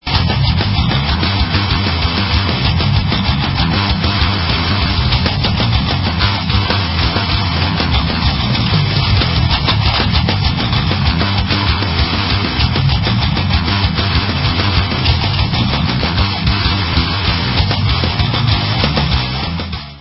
PROG. THRASH METAL